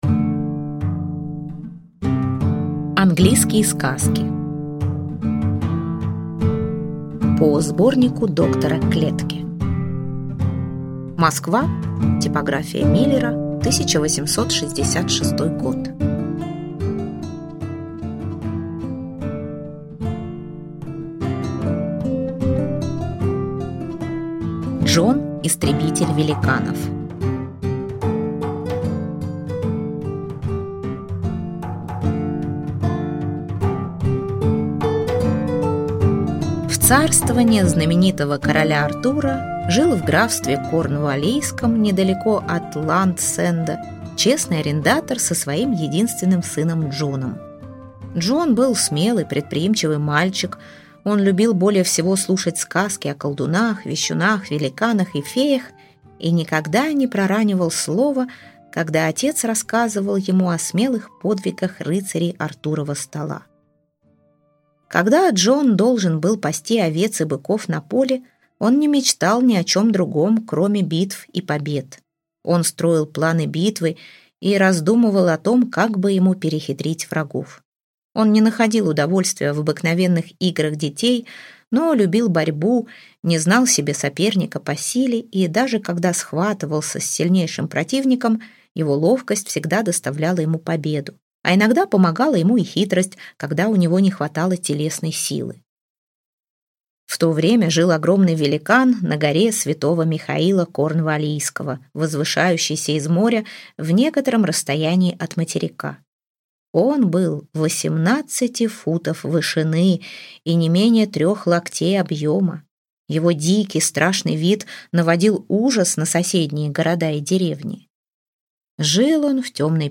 Аудиокнига Английские и шотландские сказки | Библиотека аудиокниг